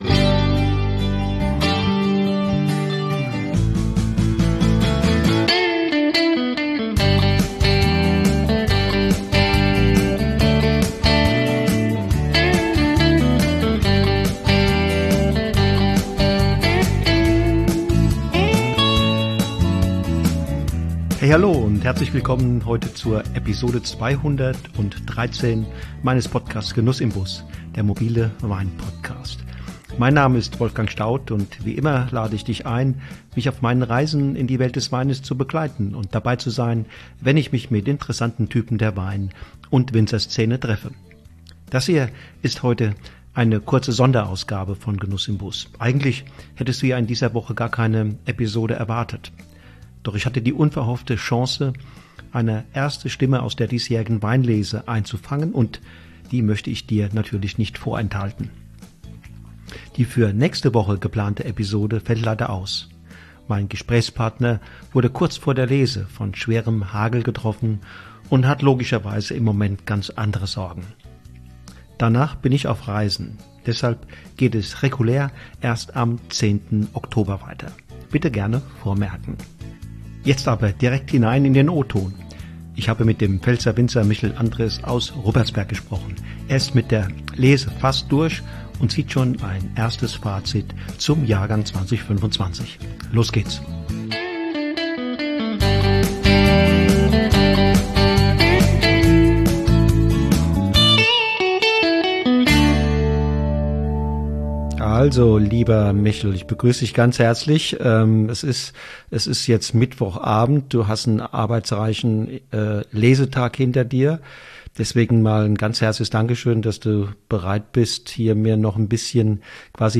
In dieser kurzen Sonderausgabe von Genuss im Bus bekommst du aktuelle Stimmen direkt aus den Weinbergen.